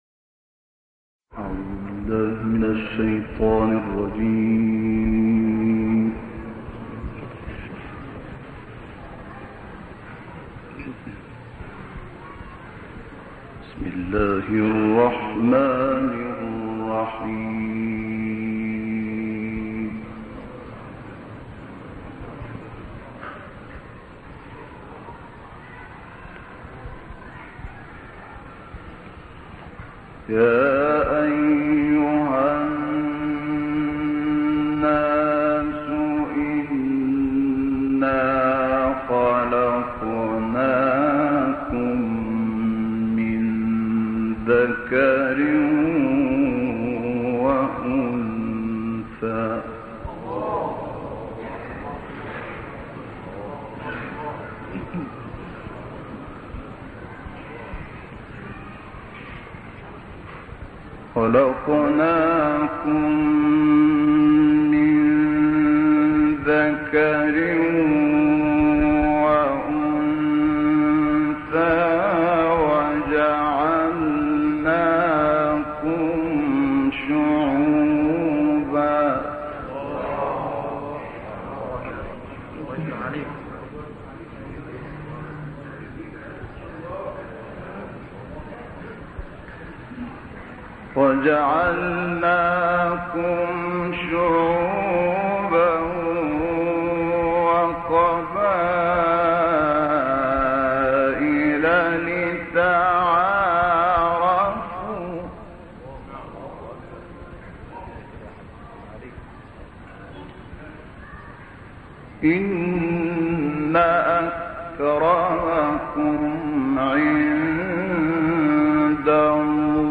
سوره حجرات با تلاوت مرحوم شحات محمد انور + دانلود/ تقوا؛ بزرگ‌ترین ارزش انسانى
گروه فعالیت‌های قرآنی: قطعه‌ای زیبا از تلاوت استاد مرحوم شحات محمد انور از آیات ۱۳ تا ۱۸ سوره حجرات و آیات ۱ تا۸ سوره قاف ارائه می‌شود.